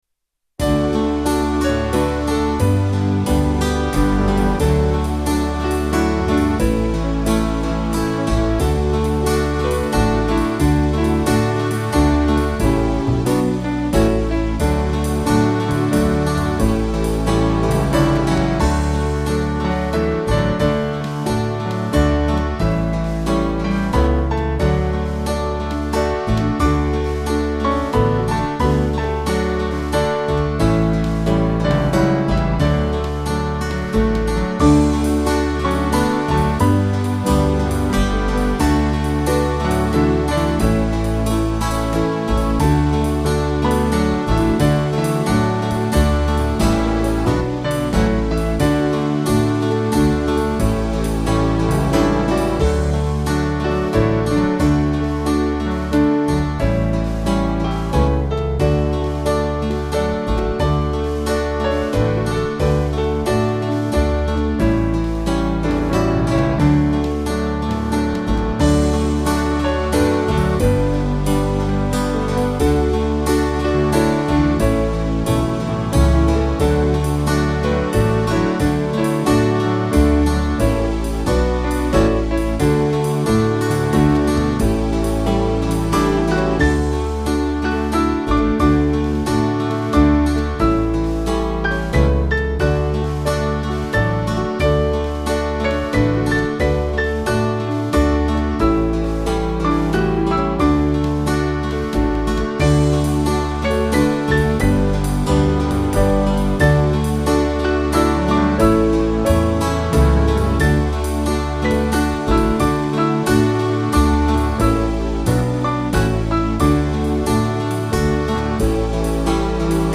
6.6.8.6 with refrain
Small Band